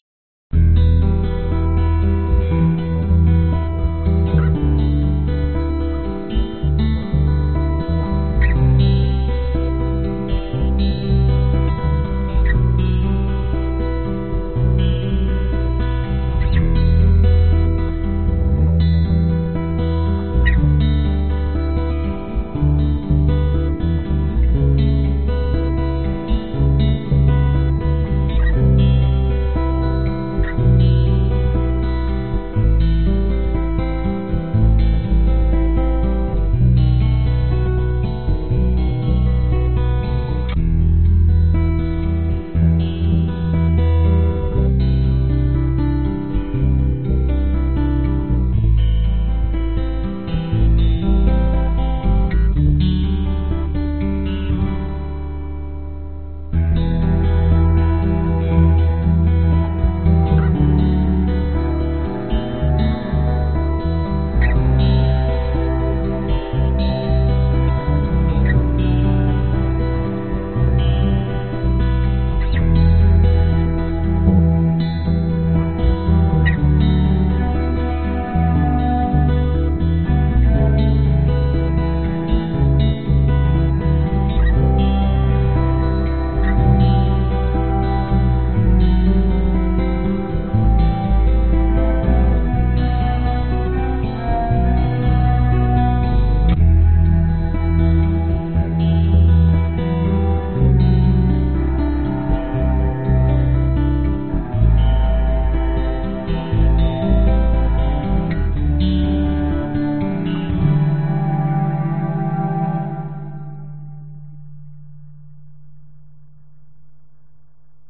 Tag: 器乐 吉他 贝斯 大提琴 低速 寒冷 浪漫 浪漫 视频用音乐 电影用音乐